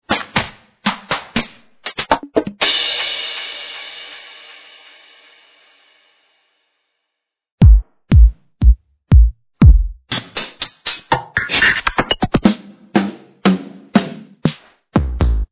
Get this finest collection of Deep House Drums, ready to be used in your Tracks!
(the prelisten files are in a lower quality than the actual packs)
flph_deephousedrums1_showcase.mp3